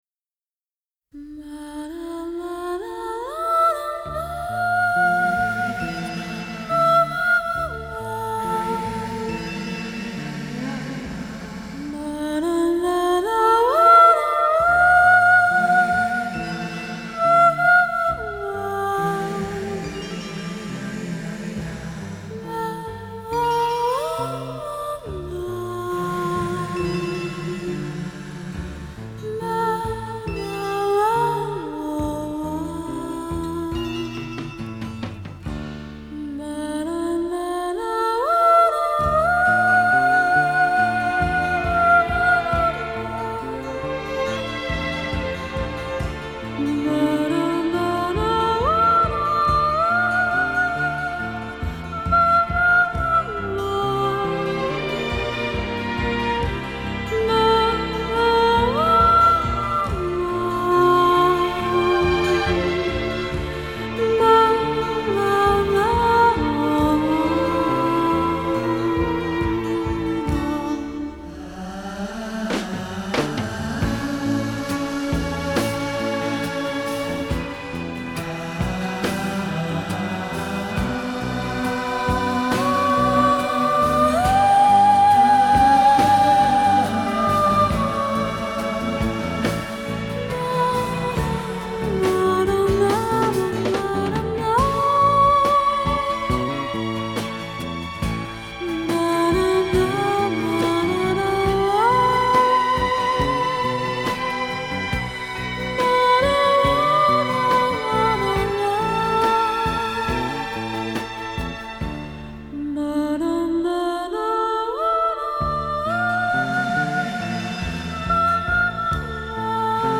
вокализ